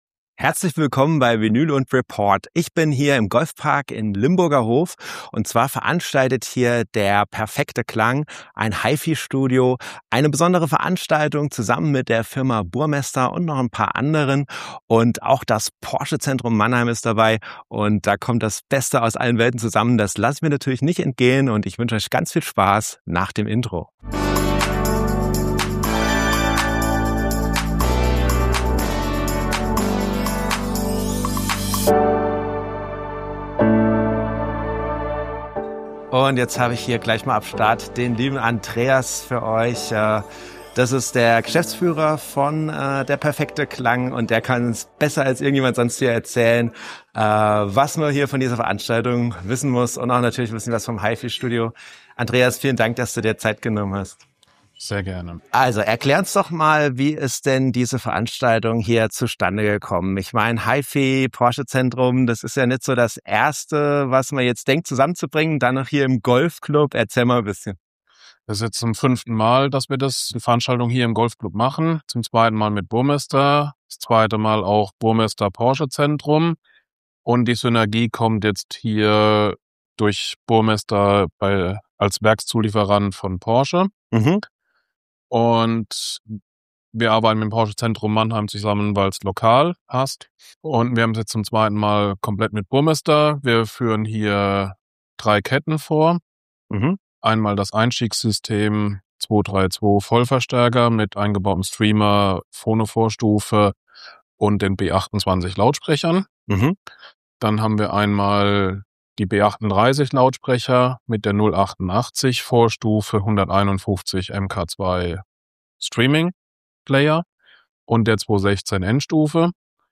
Diesmal bin ich zu Besuch bei Highway to Sound im Golfpark Kurpfalz in Limburgerhof, organisiert von der perfekte Klang aus Dudenhofen.
Ich genieße eine Hörprobe des 249 Vorverstärkers, des 259 Endverstärkers und des 257 Plattenspielers. Als Lautsprecher werden die neuen BX100 und außerdem die BC150 präsentiert.